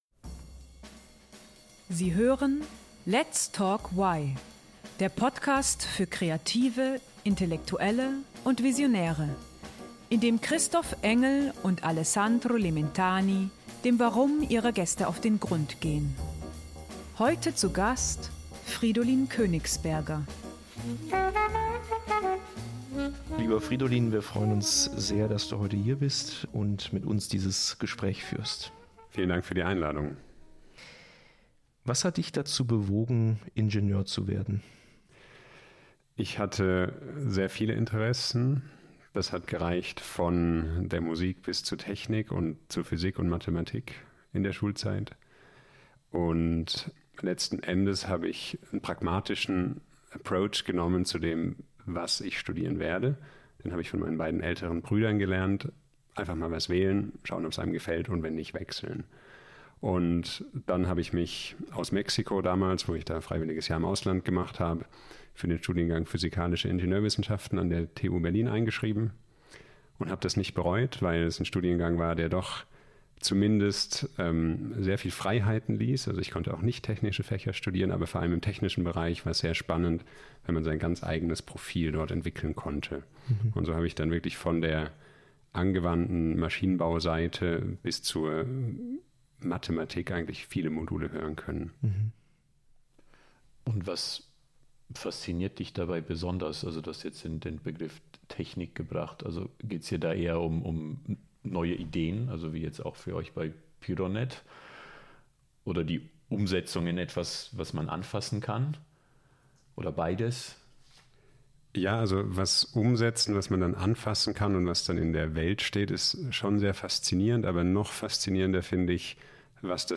Das Interview wurde am 18. Oktober 2024 aufgezeichnet.